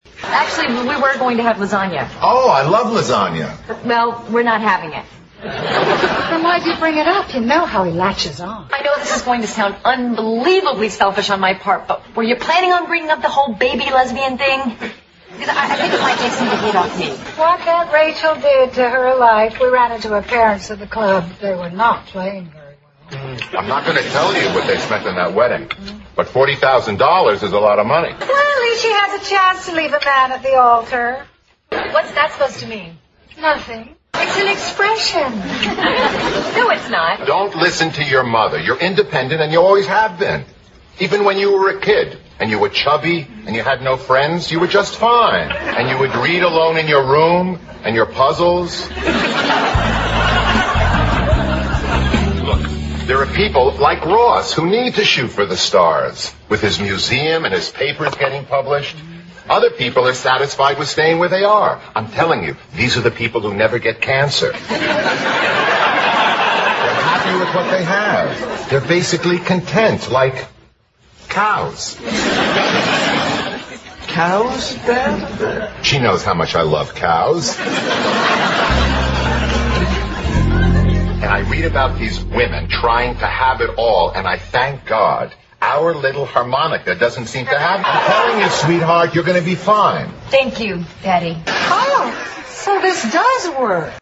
在线英语听力室老友记精校版第1季 第17期:参加助产培训班(5)的听力文件下载, 《老友记精校版》是美国乃至全世界最受欢迎的情景喜剧，一共拍摄了10季，以其幽默的对白和与现实生活的贴近吸引了无数的观众，精校版栏目搭配高音质音频与同步双语字幕，是练习提升英语听力水平，积累英语知识的好帮手。